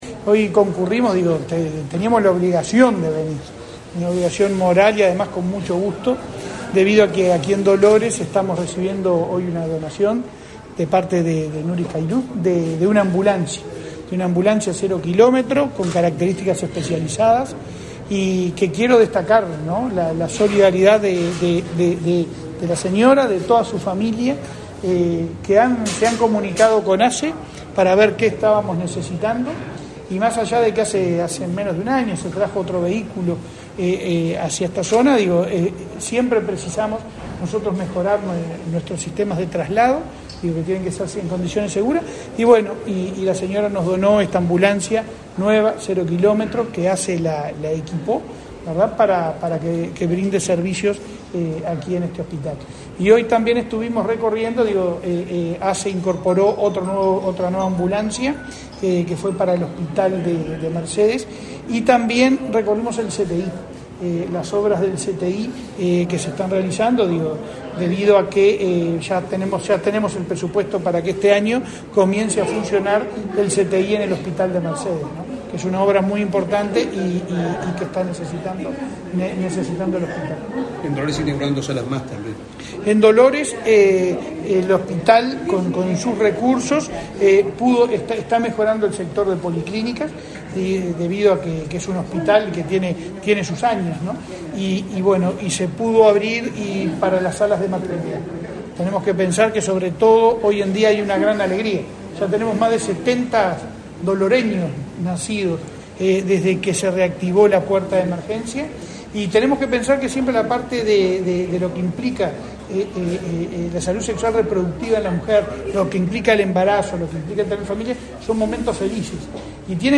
Declaraciones a la prensa del presidente de ASSE, Leonardo Cipriani
Declaraciones a la prensa del presidente de ASSE, Leonardo Cipriani 17/02/2023 Compartir Facebook X Copiar enlace WhatsApp LinkedIn Tras participar en la entrega de una ambulancia y la inauguración de obras en el hospital de Dolores, este 16 de febrero, el presidente de la Administración de los Servicios de Salud del Estado (ASSE), Leonardo Cipriani, realizó declaraciones a la prensa.